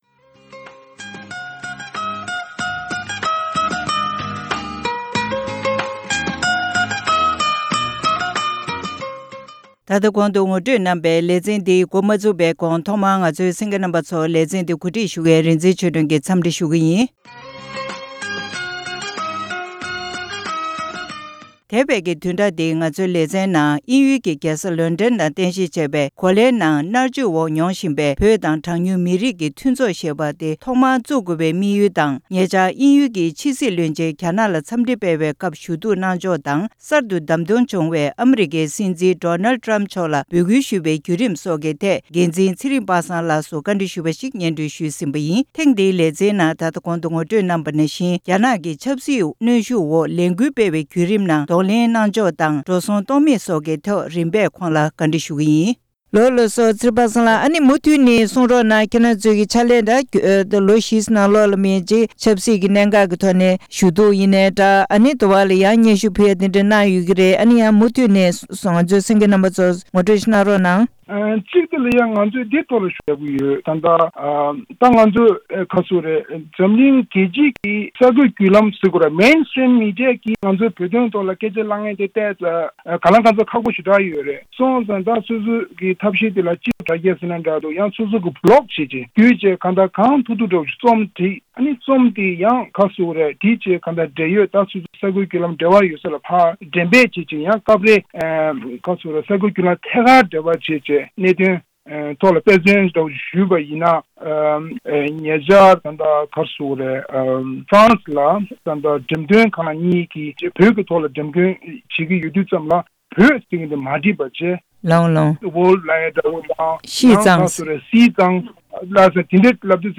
གནས་འདྲི་ཞུས་པ་ཞིག་གཤམ་ལ་གསན་གནང་གི་རེད།